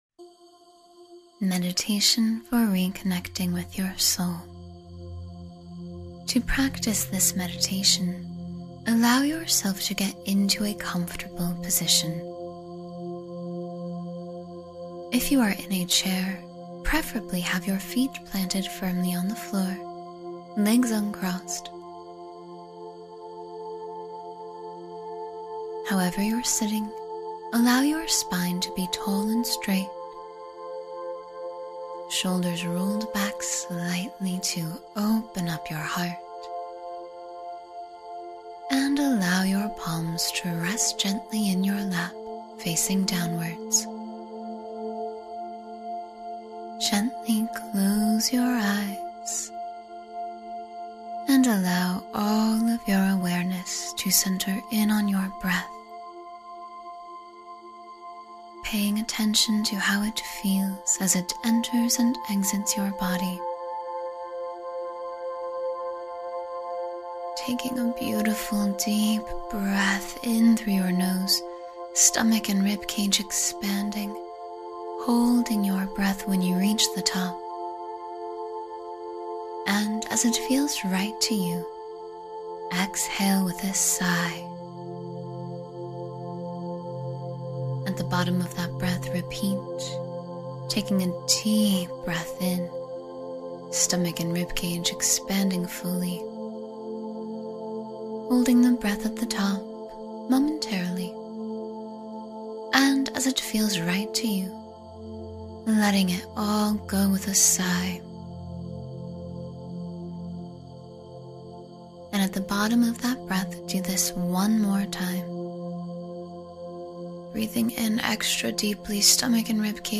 Connect with Your Inner Light and Soul — Meditation for Self-Discovery